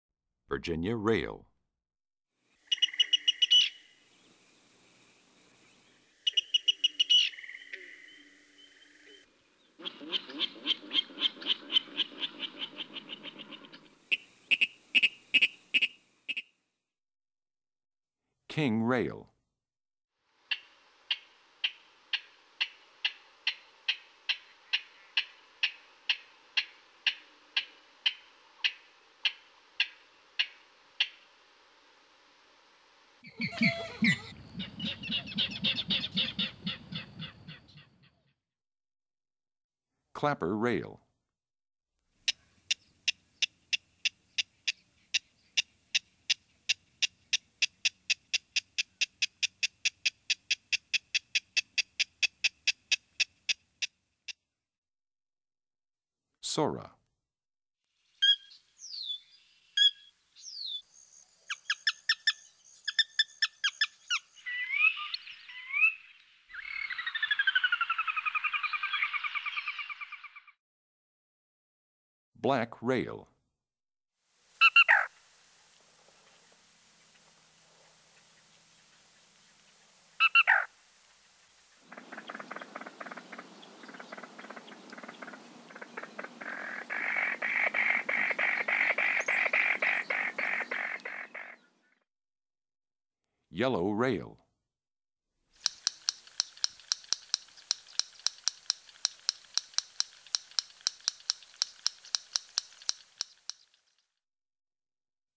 07 Virginia Rail,King Rail,Clapper Rail,Sora,Black Rail,Yellow Rail.mp3